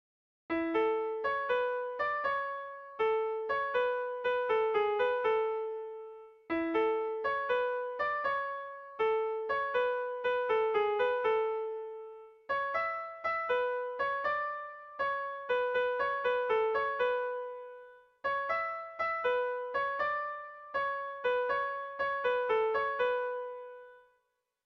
Gabonetakoa
AABB